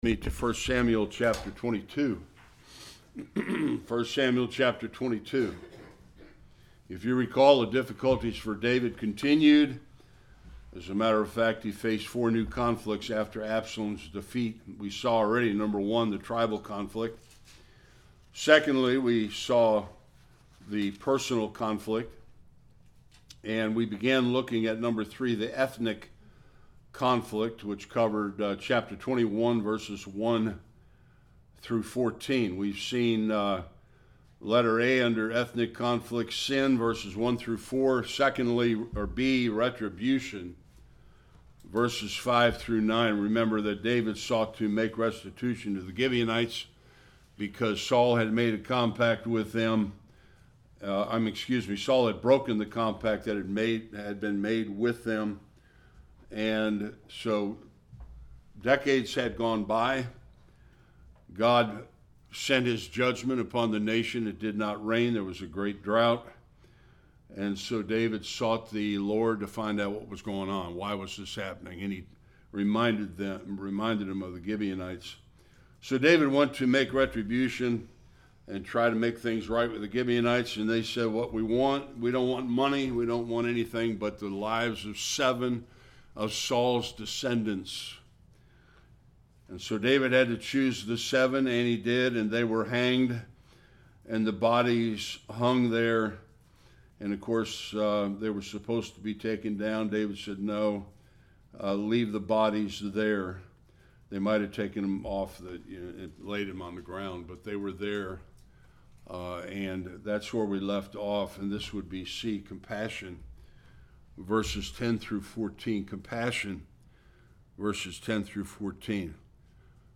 10-22 Service Type: Sunday School David shows compassion regarding the dead bodies of Saul and his sons.